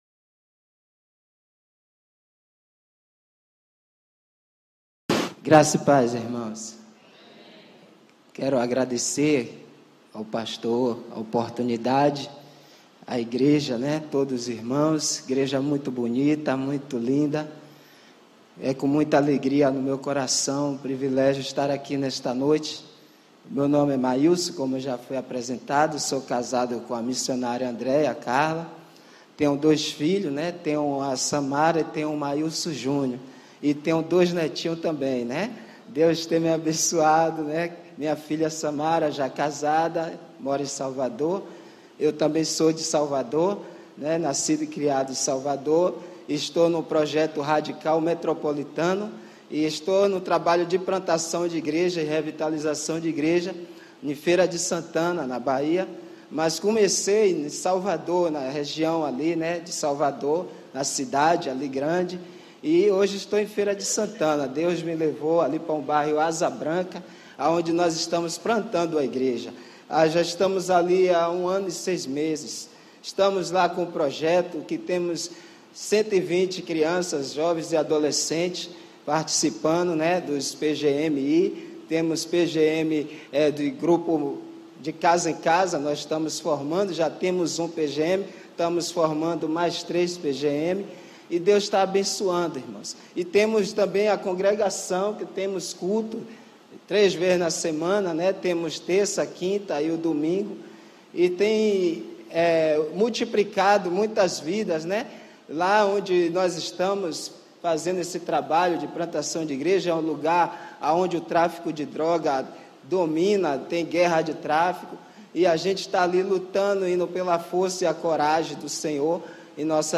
Mensagem
na Primeira Igreja Batista do IPS.